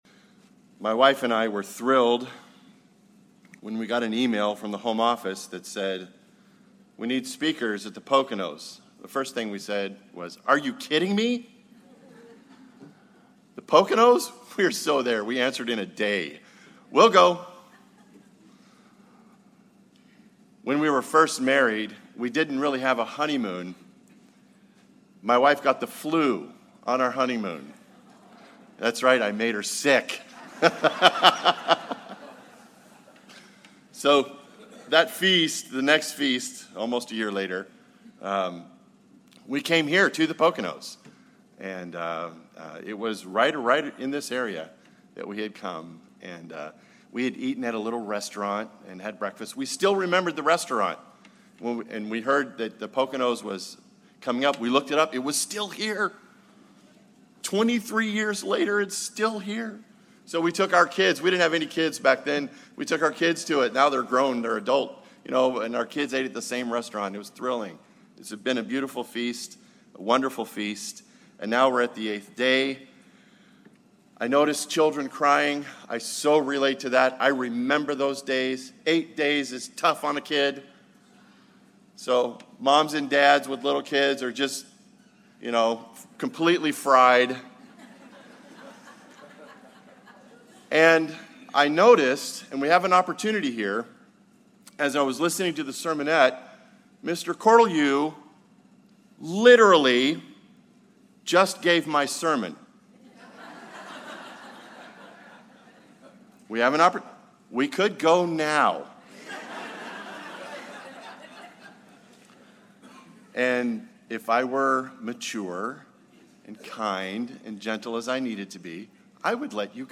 This sermon was given at the White Haven, Pennsylvania 2017 Feast site.